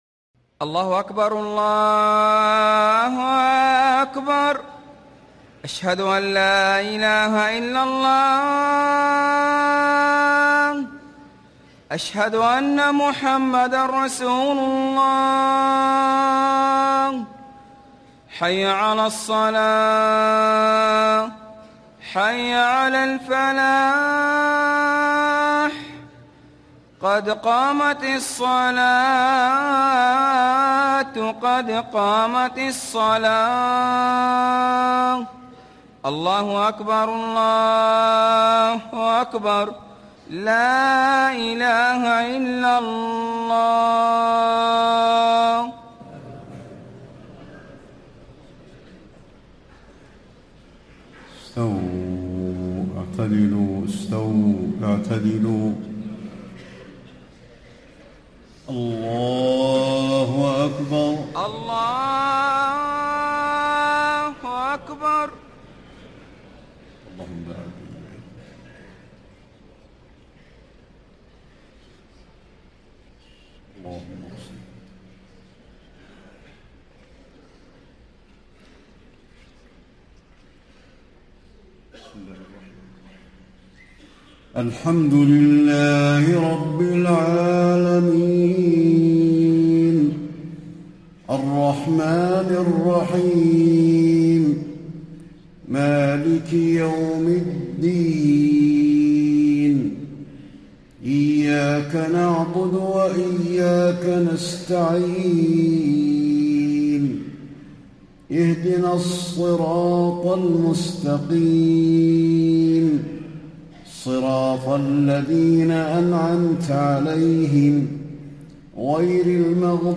صلاة العشاء 8 - 1 - 1435هـ من سورة آل عمران > 1435 🕌 > الفروض - تلاوات الحرمين